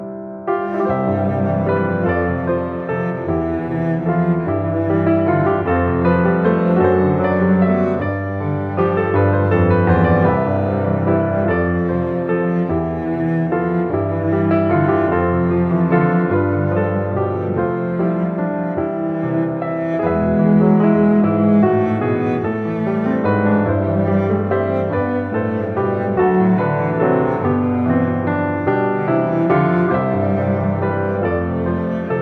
arranged for piano and light instrumentals